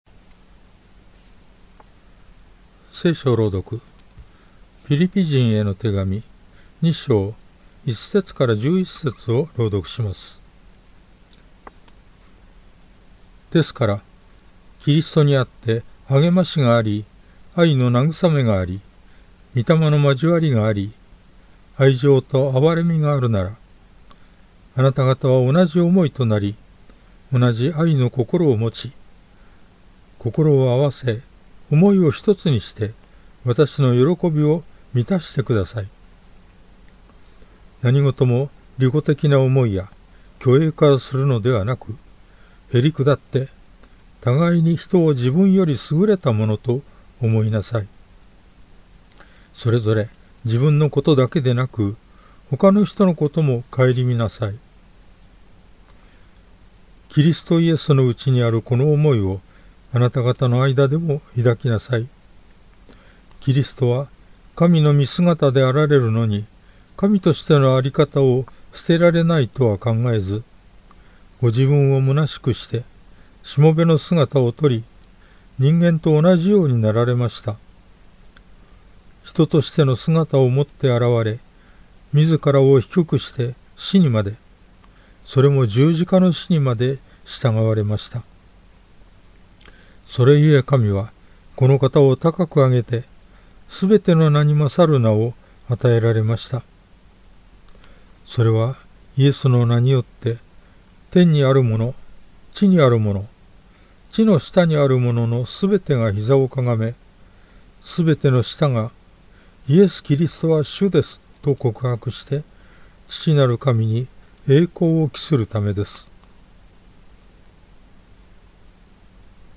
BibleReading_Phi2.1-11.mp3